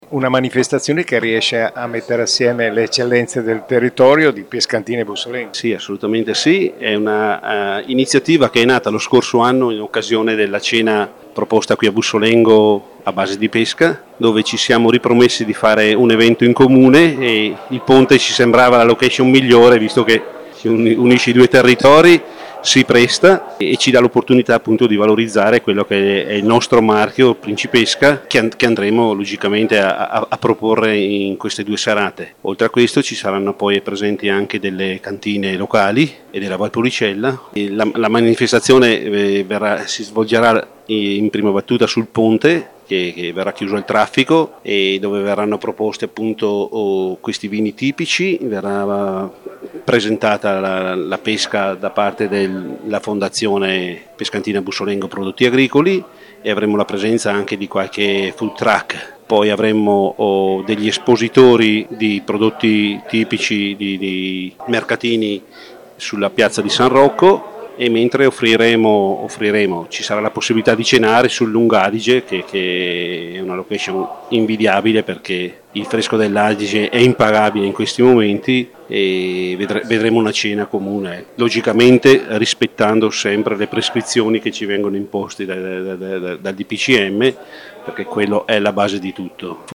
A parlare dell’evento, al microfono del nostro corrispondente
Davide Quarella, sindaco di Pescantina